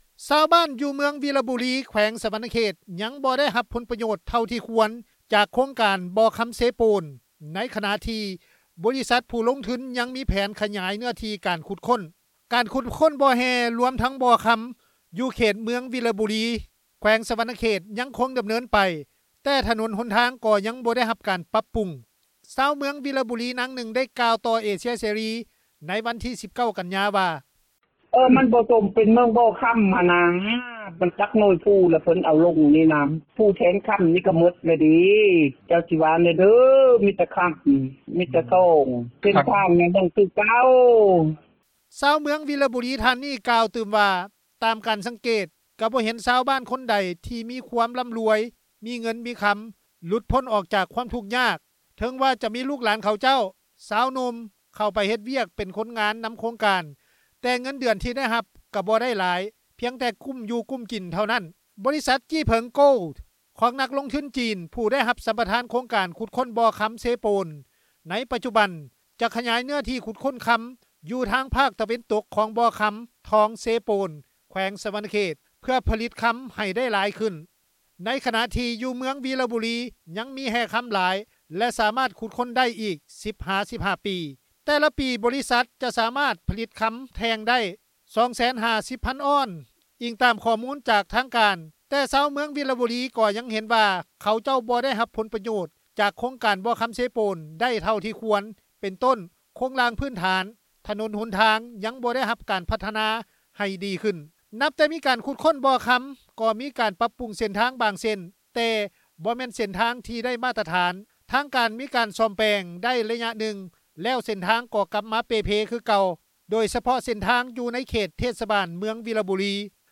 ຊາວເມືອງວີລະບູລີ ນາງນຶ່ງ ໄດ້ກ່າວຕໍ່ເອເຊັຽເສຣີ ໃນວັນທີ 19 ກັນຍາ ວ່າ:
ຊາວບ້ານອີກ ທ່ານນຶ່ງ ໄດ້ກ່າວຕໍ່ເອເຊັຽເສຣີ ໃນວັນທີ 19 ກັນຍາ ວ່າ: